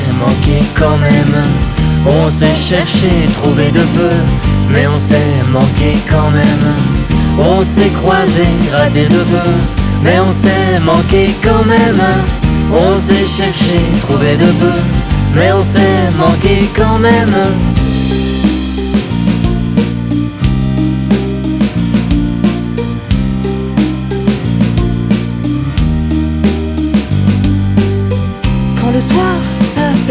Le son est chaud, les deux voix s'accordent, se répondent.